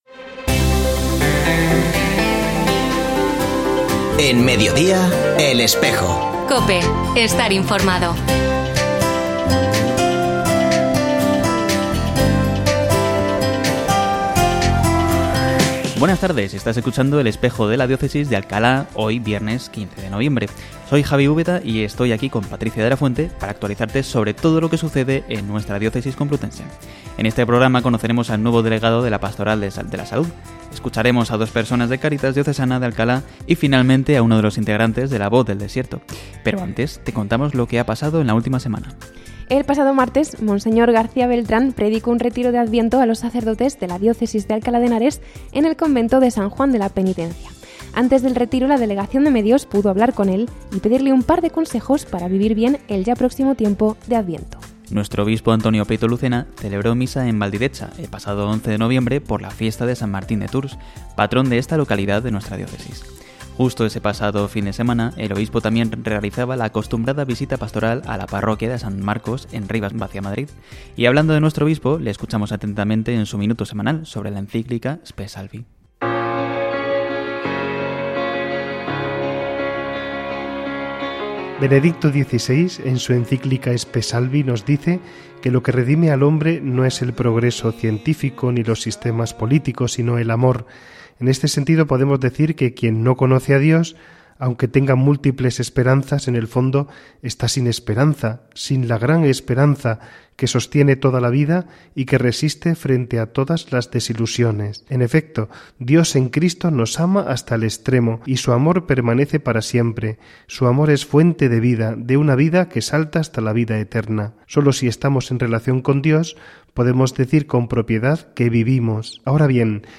Escucha otras entrevistas de El Espejo de la Diócesis de Alcalá
Se ha vuelto a emitir hoy, 15 de noviembre de 2024, en radio COPE.